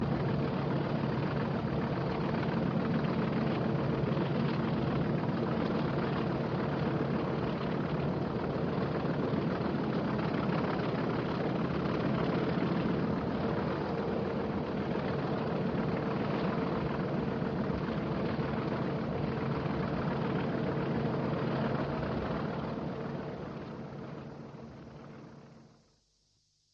Scottish Diesels 40166 leaving Gleneagles
40.166 leaving Gleneagles on 11.17 Aberdeen-Glasgow service on 4.7.75.